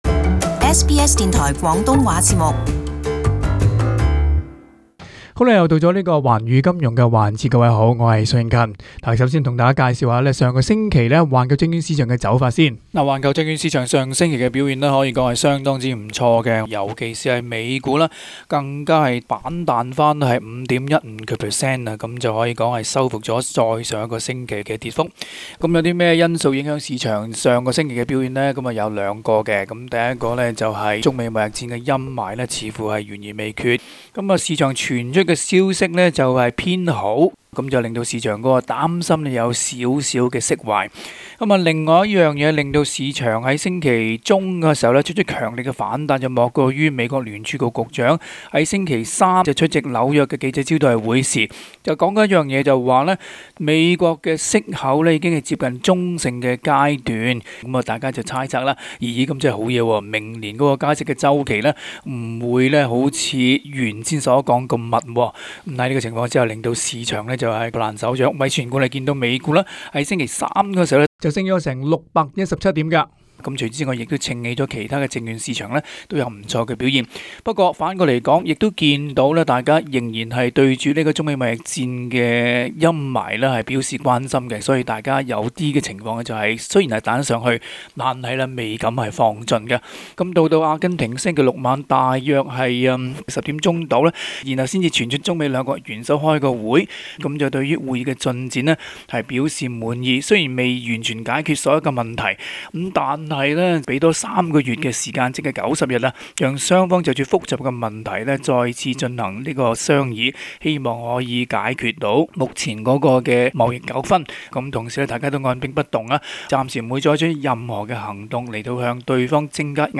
AAP Source: AAP SBS广东话播客 View Podcast Series Follow and Subscribe Apple Podcasts YouTube Spotify Download (17.73MB) Download the SBS Audio app Available on iOS and Android 举世触目的中美两国元首会议终于在阿根廷首都布宜洛斯艾利斯预期举行。